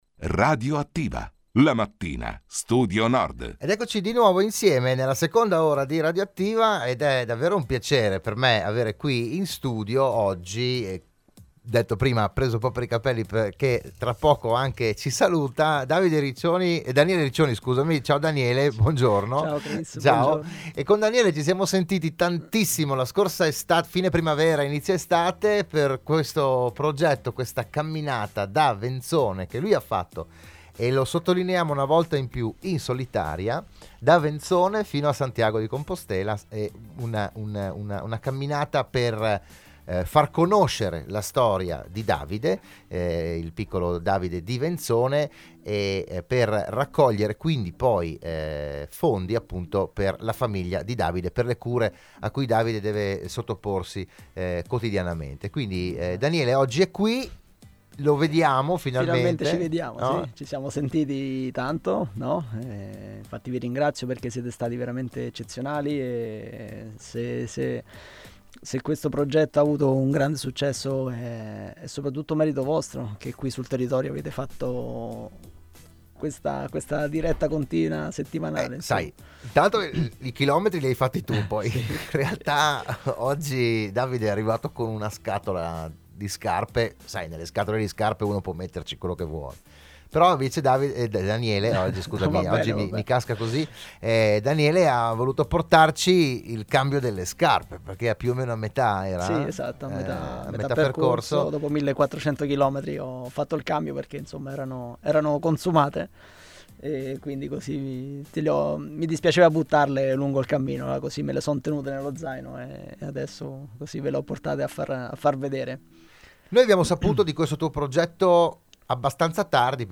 Un ospite speciale nella puntata odierna di “RadioAttiva“, la trasmissione di Radio Studio Nord